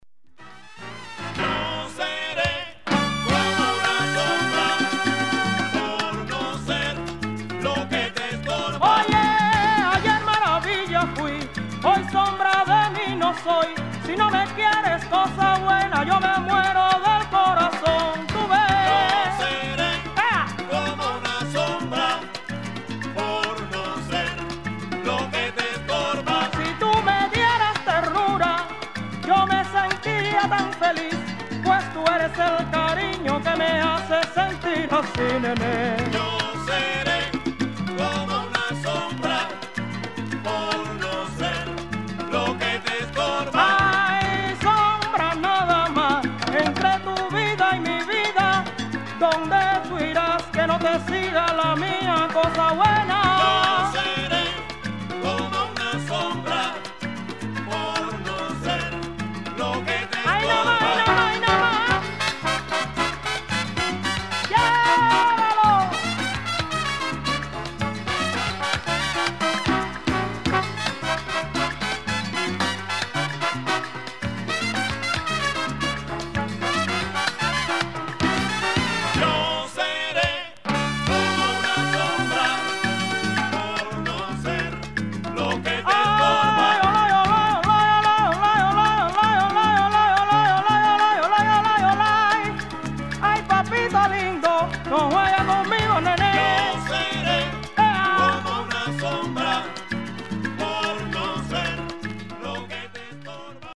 女性ボーカル
SALSA/GUARACHA
CONDITION 見た目VG(+),音はVG+/VG+ (リングウェアー , 経年劣化) (VINYL/JACKET)
1980年代初頭の録音と思われる。
パンチの効いた切れのある黒いボーカルが”サルサ”な演奏にぴったりはまる。